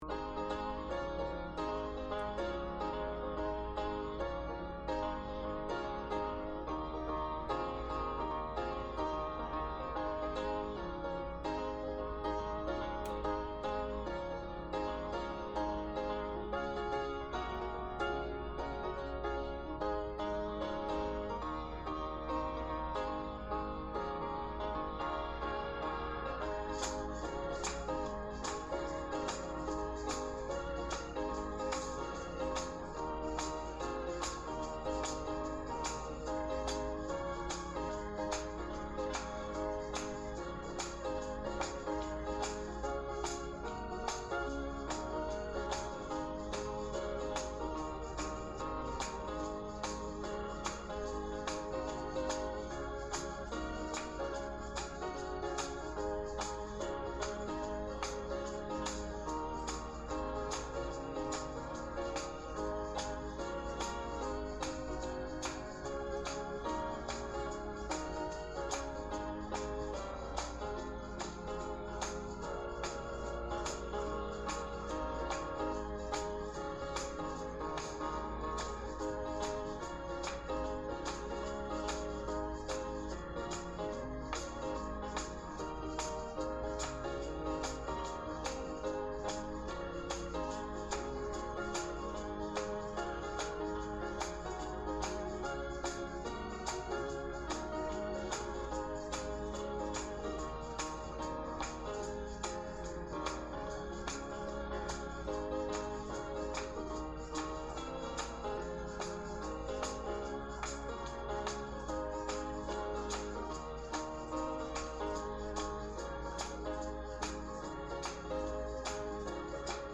JoyfulChurchMusic.mp3